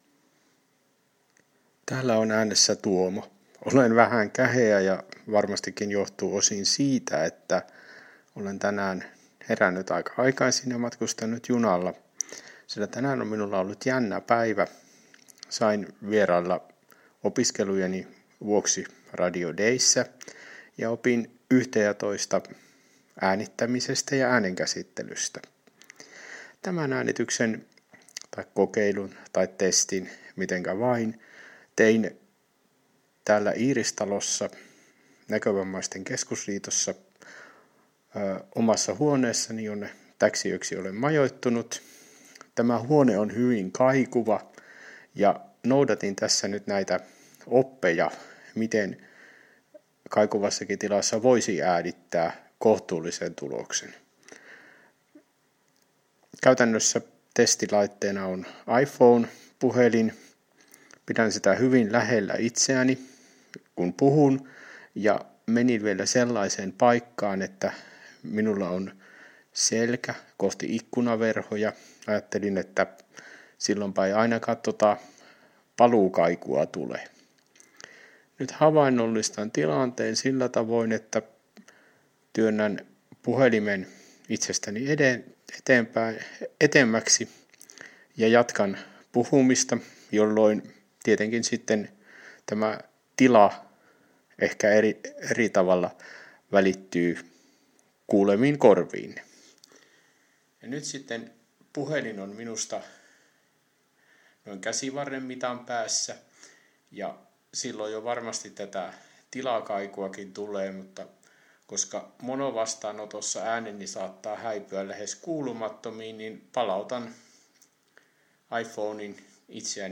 Äänityskokeilu kaikuvassa asuinhuoneessa